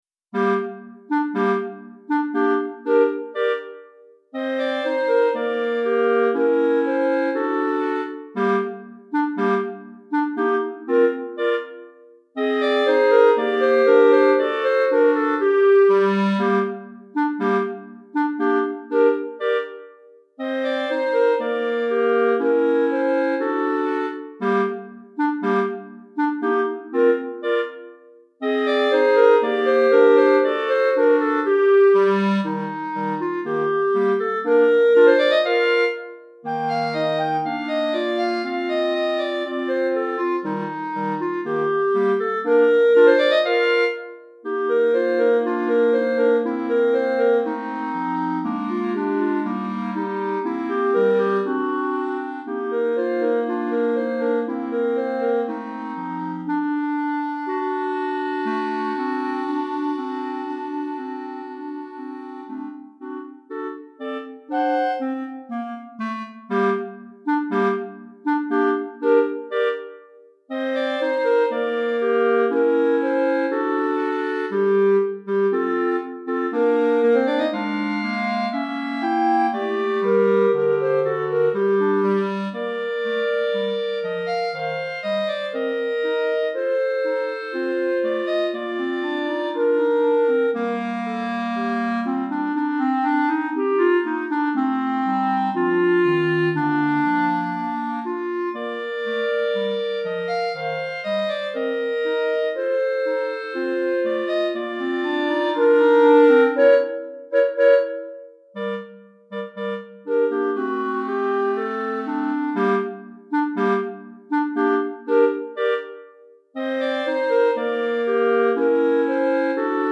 3 Clarinettes en Sib